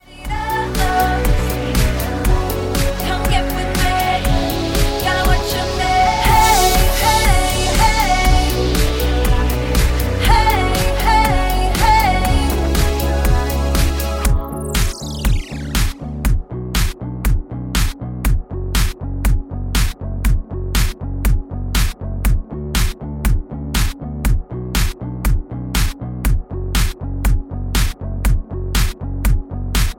Backing track files: 2010s (1044)